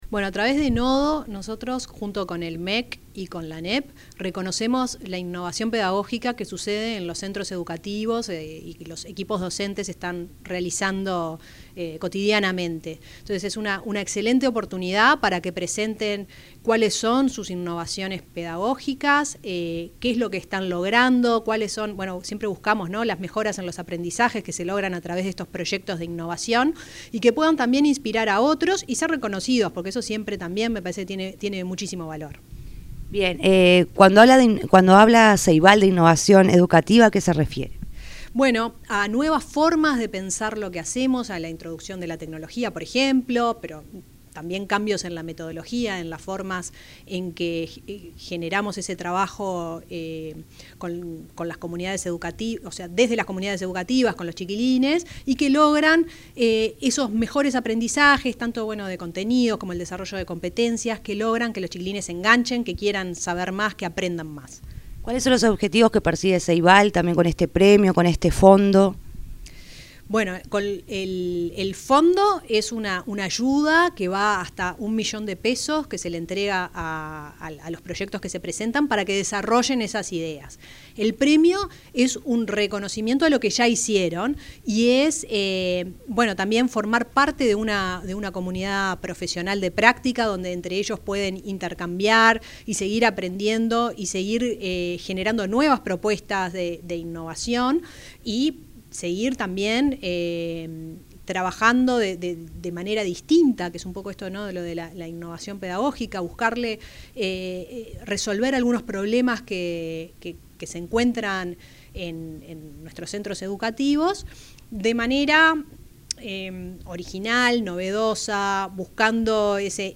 Declaraciones de la presidenta de Ceibal, Fiorella Haim